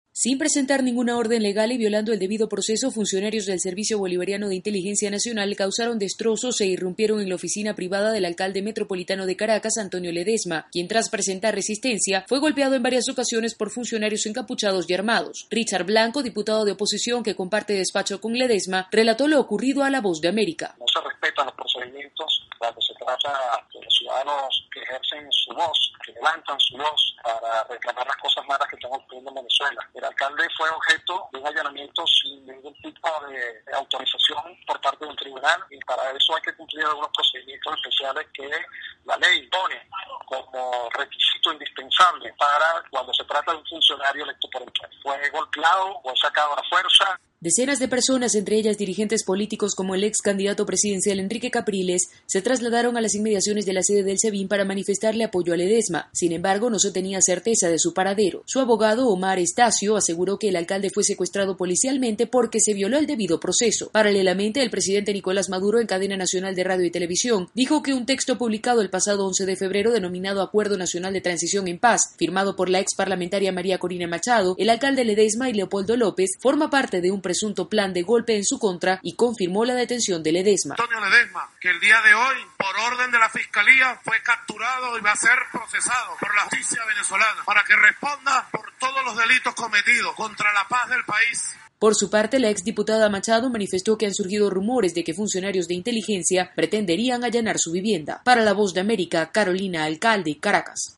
El presidente Nicolás Maduro lo acusa de formar parte de un plan conspirativo contra su gobierno. Desde Caracas informa